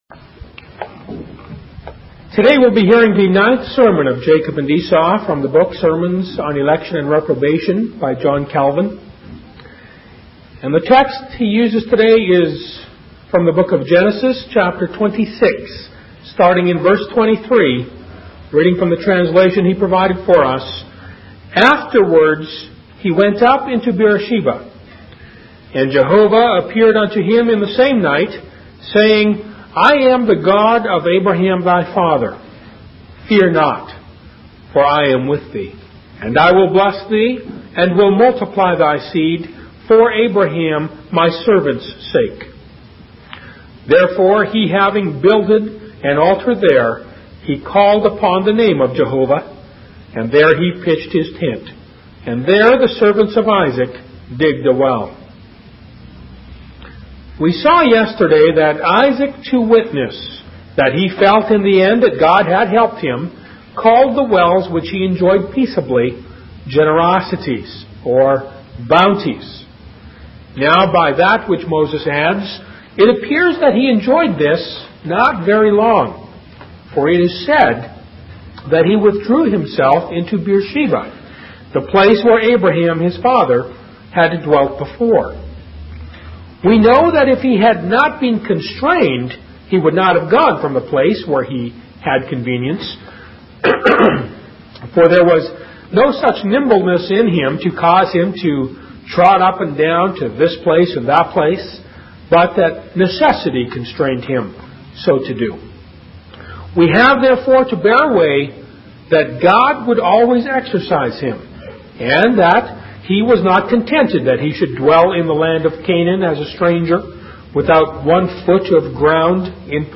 In this sermon, the preacher emphasizes the importance of meditating on God's word and holding onto His promises, even in the midst of suffering and afflictions. He encourages believers to submit to God's will and glorify Him sincerely, without any pretense or hypocrisy.